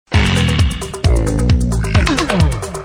Категория: Голосовые рингтоны